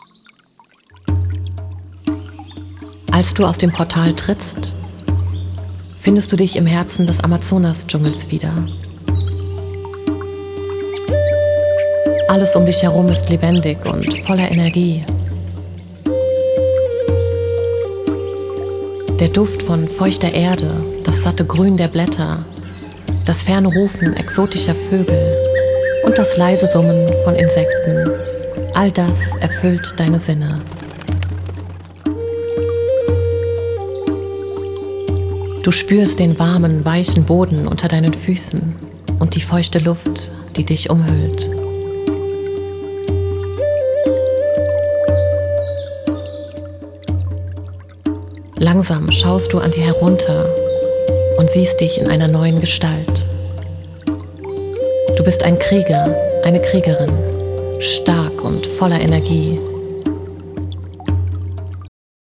Frequenz: 741 Hz – Stärkt innere Stärke und Befreiung von Blockaden.
8D-Musik: Versetzt dich mitten in den Amazonas, verstärkt Krieger-Energie.